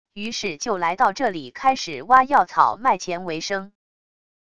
于是就来到这里开始挖药草卖钱为生wav音频生成系统WAV Audio Player